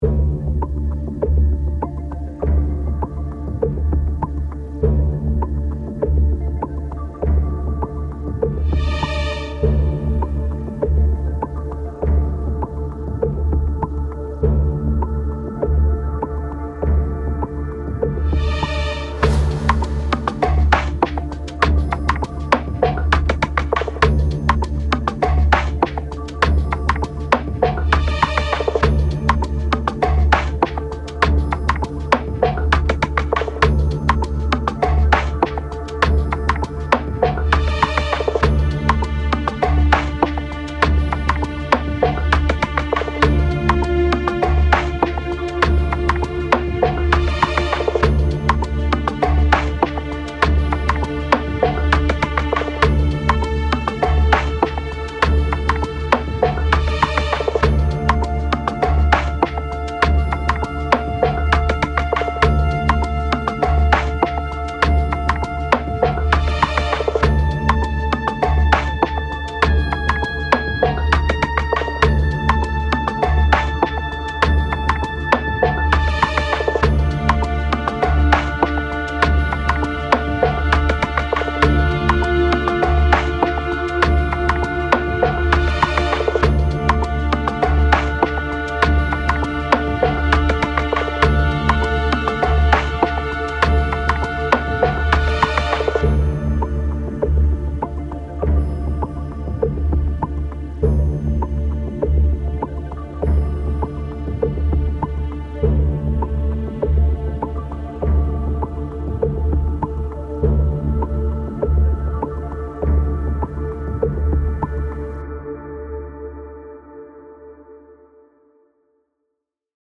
仄かに危機感を感じさせるシネマティック音楽素材
シネマチック 2:00 ダウンロード